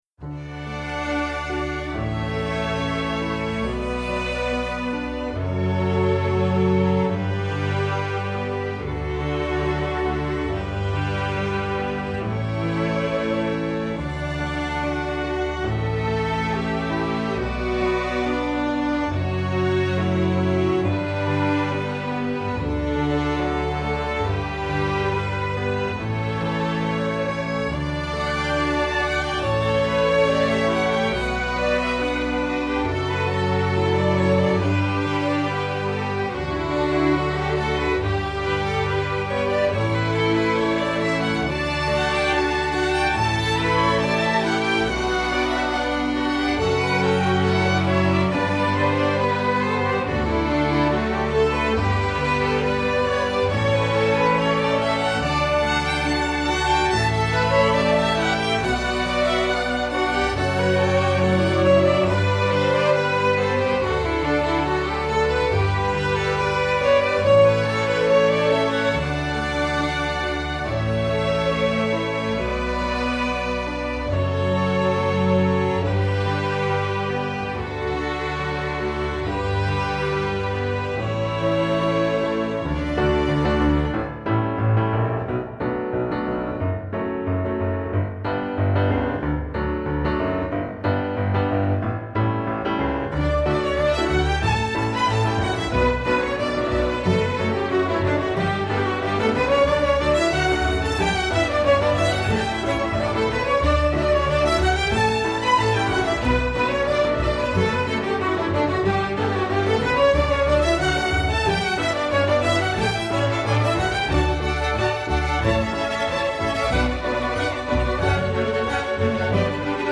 3rd violin, viola [tc] part:
Cello part:
String bass part:
Piano accompaniment part: